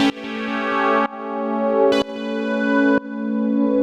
GnS_Pad-dbx1:2_125-A.wav